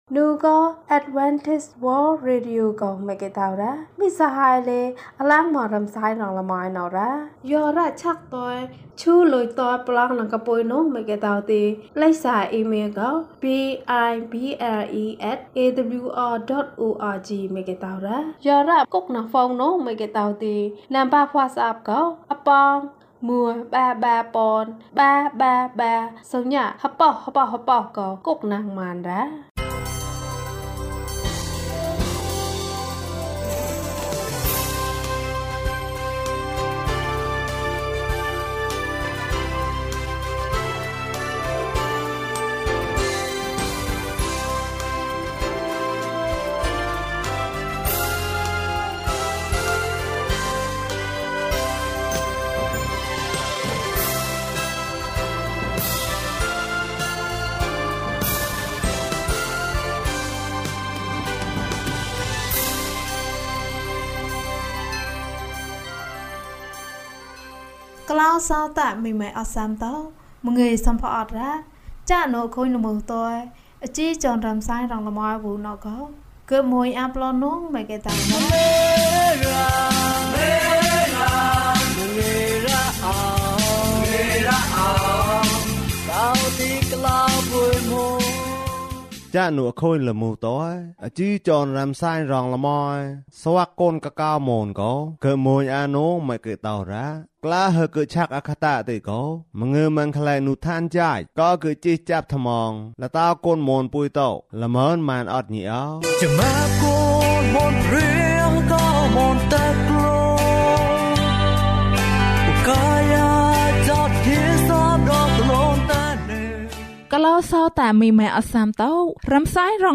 ဘုရားသခင်သည် ချစ်ခြင်းမေတ္တာဖြစ်သည်။၀၈ ကျန်းမာခြင်းအကြောင်းအရာ။ ဓမ္မသီချင်း။ တရားဒေသနာ။